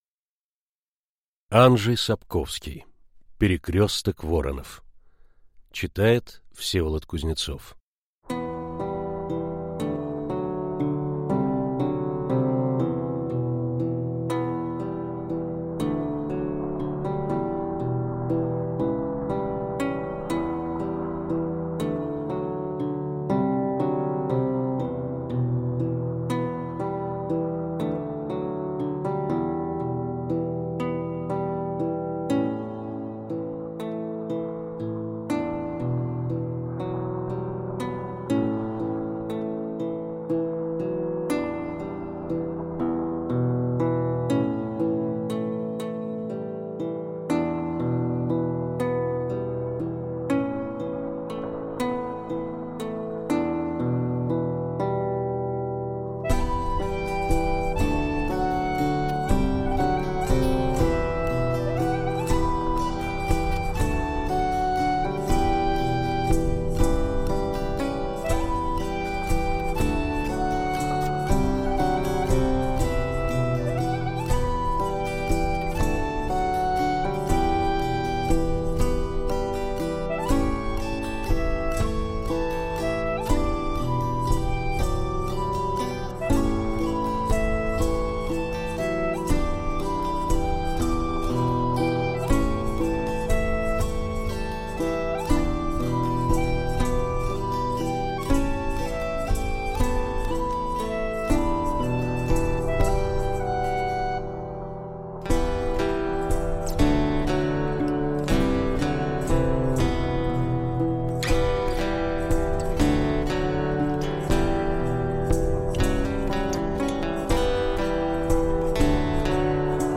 Аудиокнига Перекрёсток воронов из серии Ведьмак - Скачать книгу, слушать онлайн